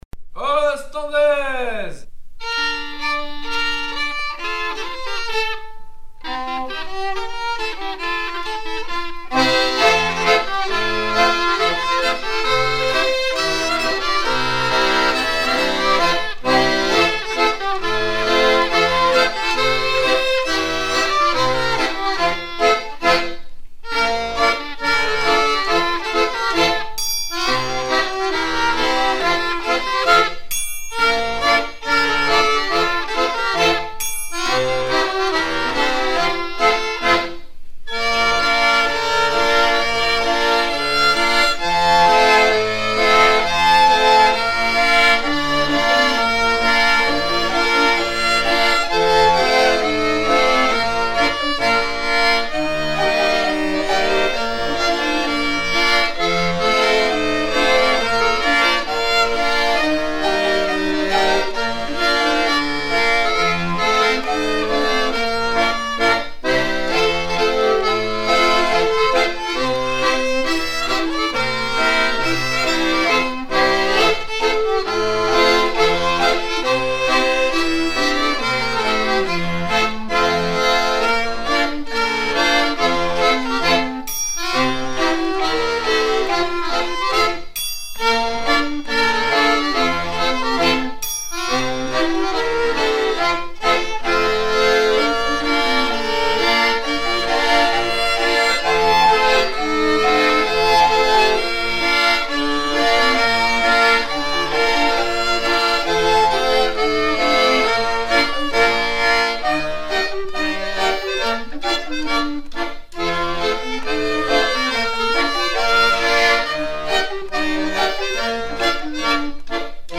Air à danser de Flandre